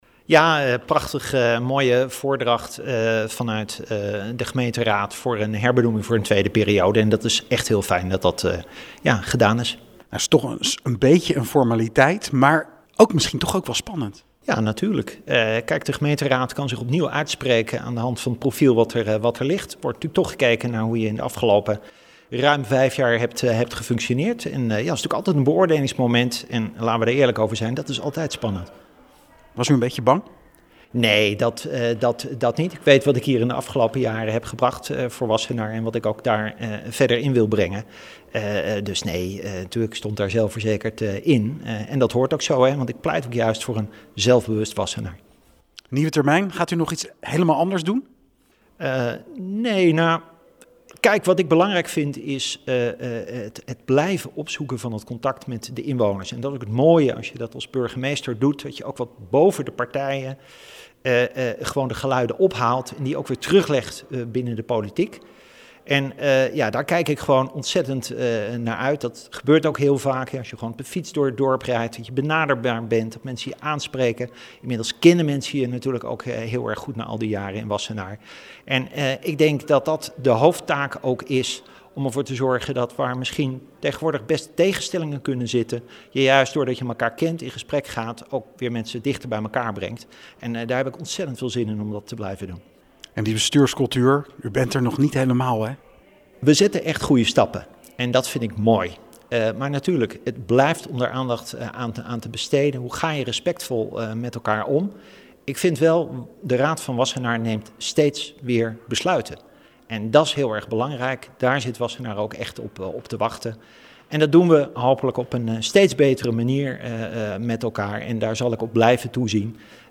Burgemeester Leendert de Lange over zijn herbenoeming.